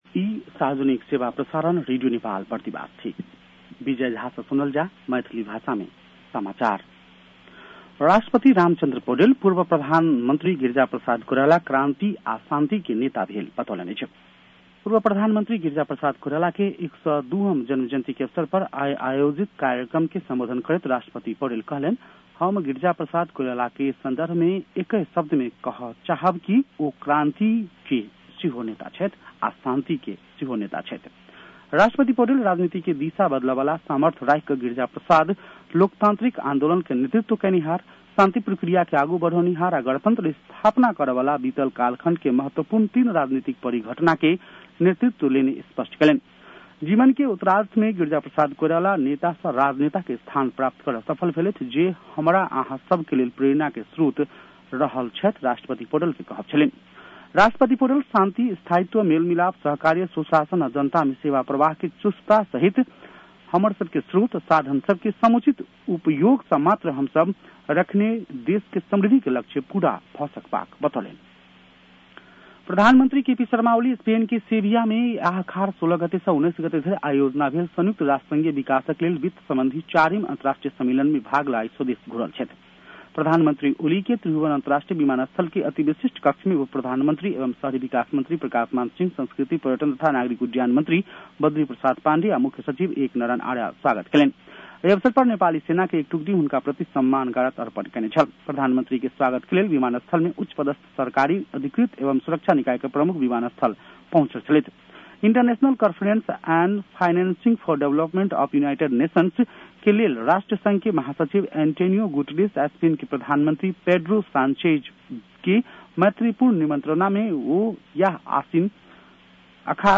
मैथिली भाषामा समाचार : २० असार , २०८२